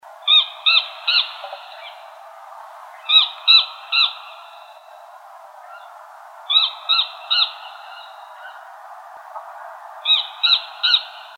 Rhinocrypta lanceolata
Lo vió el guía,al pasar cerca un zorro comenzó a vocalizar
Nome em Inglês: Crested Gallito
Localidade ou área protegida: Reserva Natural Formosa
Condição: Selvagem
Certeza: Gravado Vocal